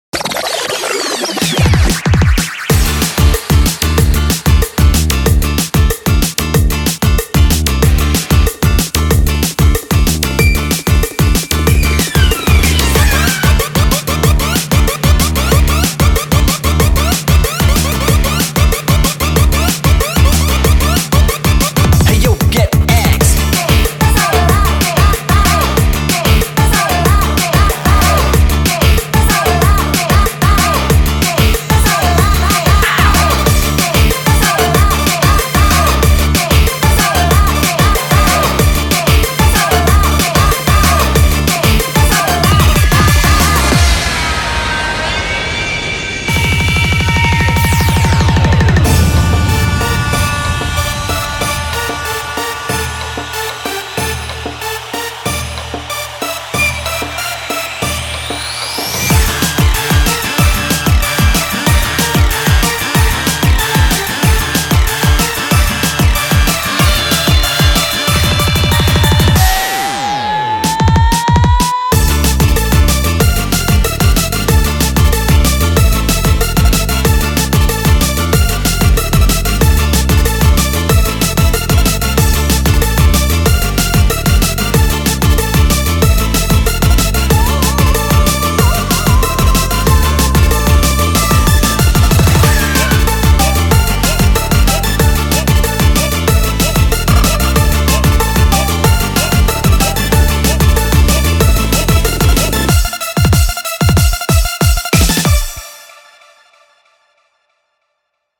BPM94-187
Audio QualityPerfect (High Quality)
Realized its true bpm is actually 187.22 bpm.